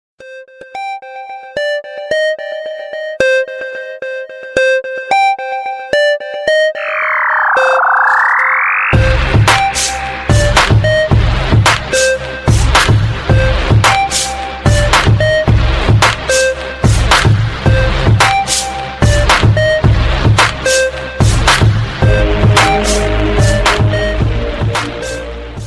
Хип Хоп Бит